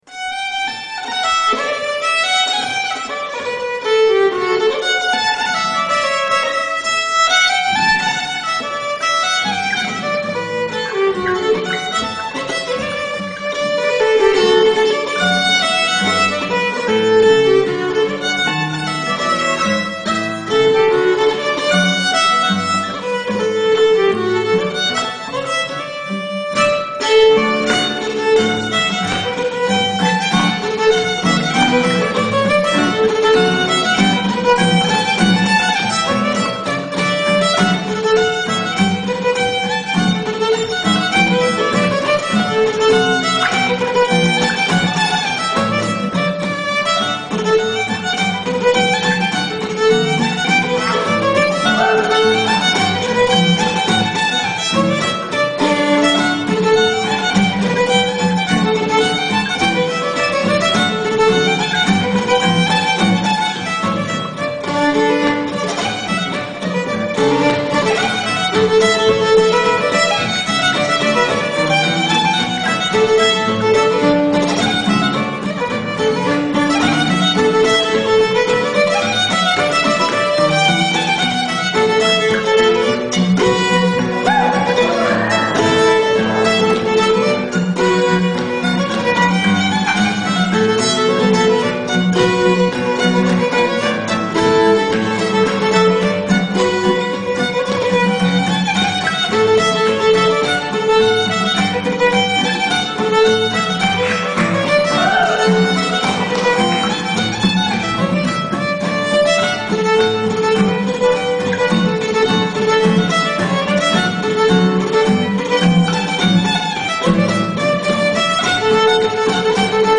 Hornpipe
Fiddle Guitar